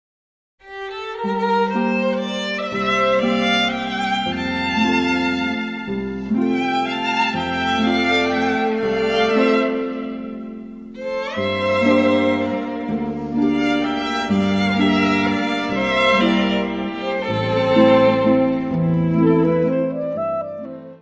Music Category/Genre:  Soundtracks, Anthems, and others